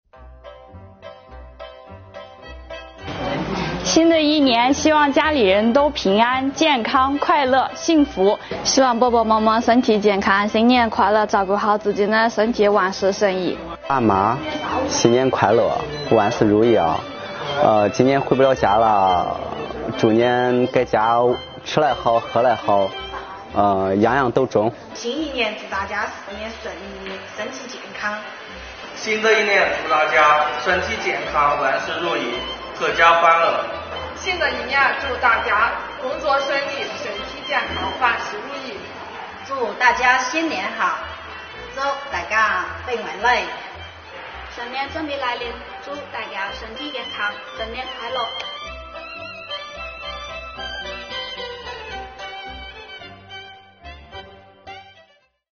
来自五湖四海的广西税务干部，
用家乡话给大家送祝福啦！